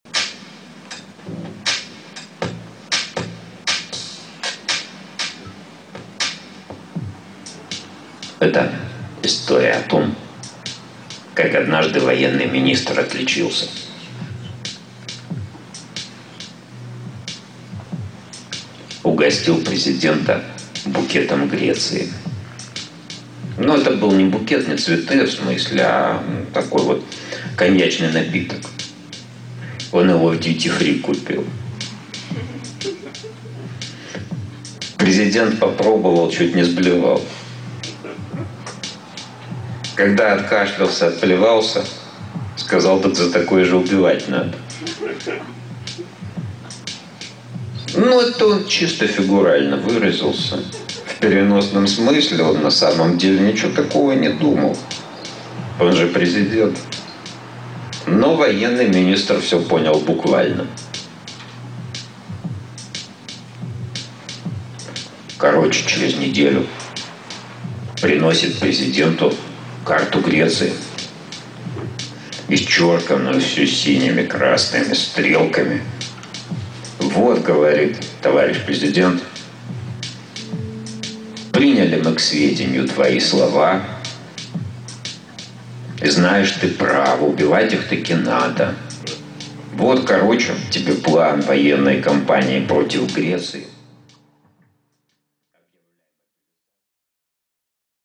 Аудиокнига Про супер-оружие | Библиотека аудиокниг